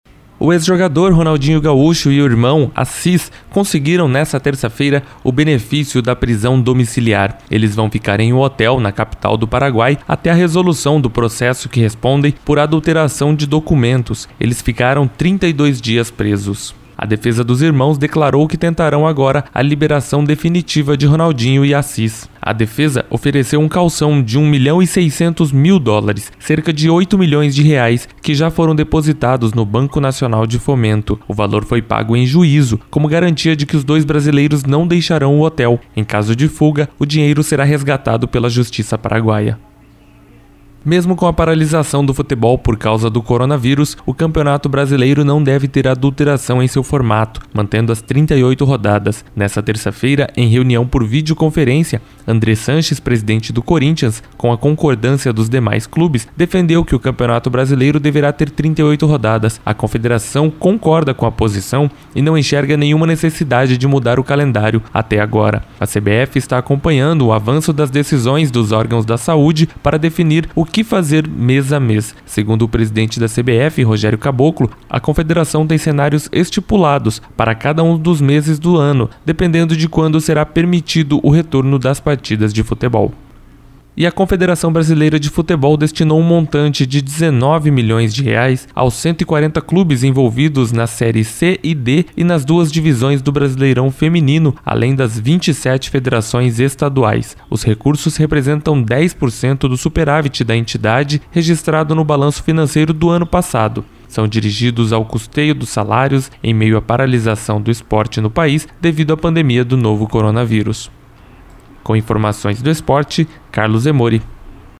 Giro Esportivo SEM TRILHA